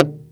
CLUNK.wav